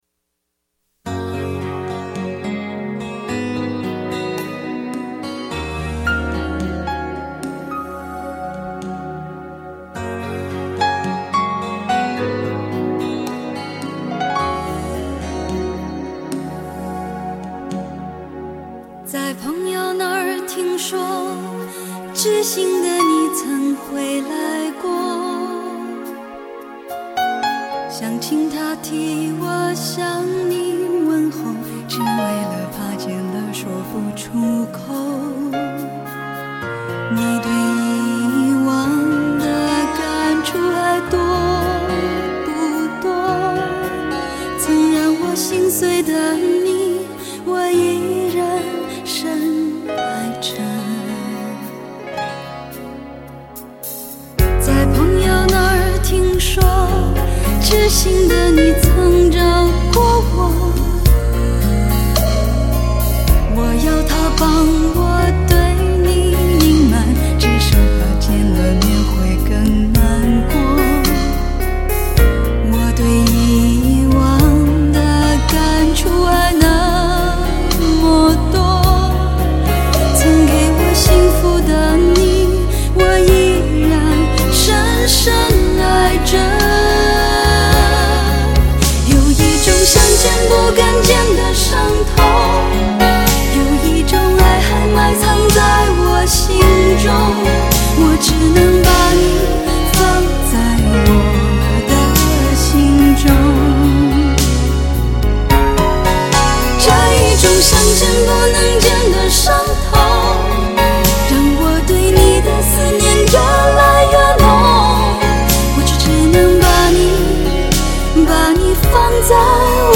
忧伤而华丽的演唱，将女人细微敏感的心思铺展于耳际